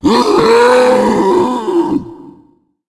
Tank_Death_07.wav